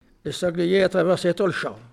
Olonne-sur-Mer
locutions vernaculaires